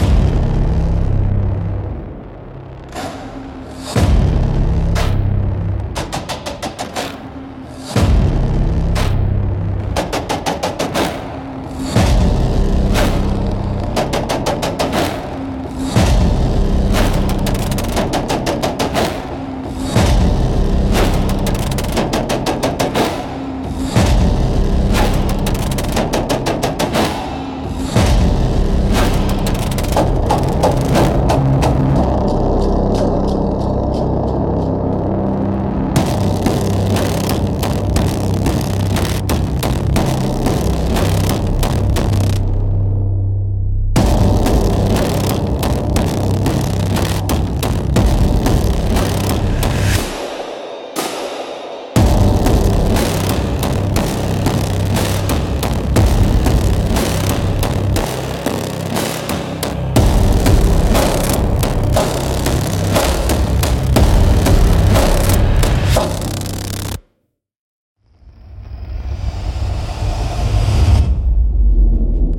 Instrumental - Echoes from the Grid - 1.12